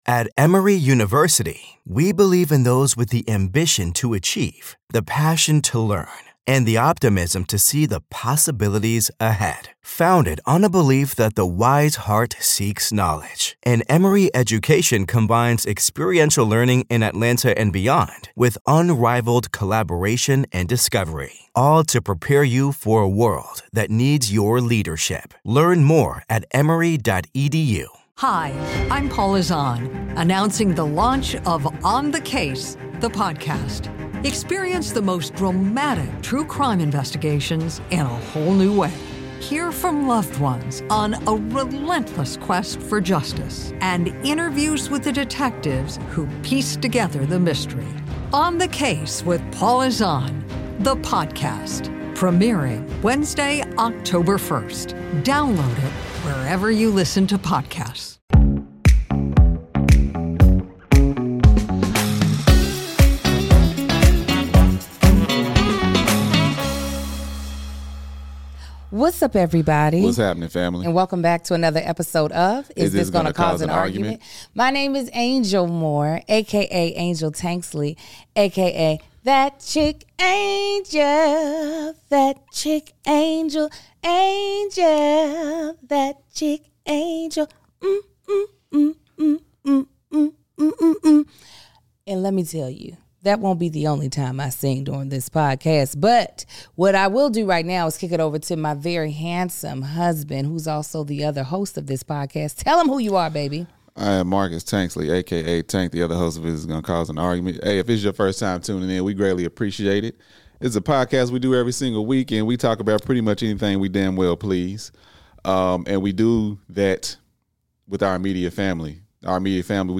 Plus a special guest pops his head in.